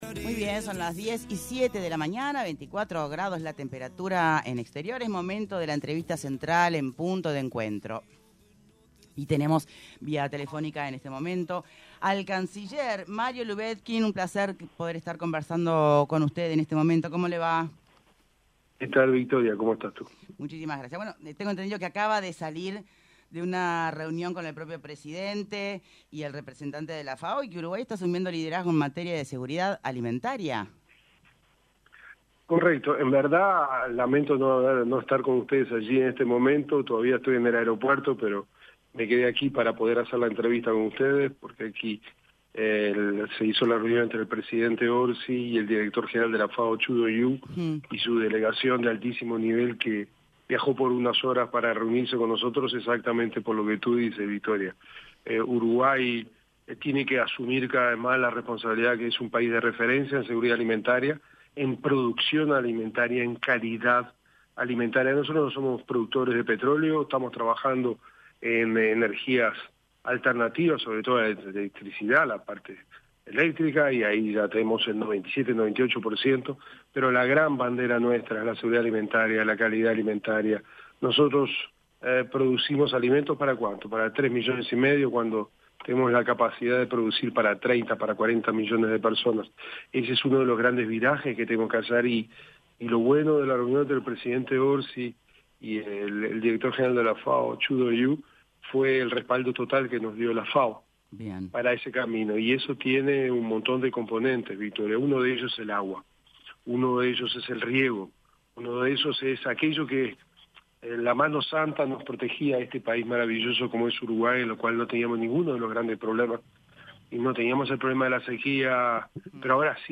ENTREVISTA: MARIO LUBETKIN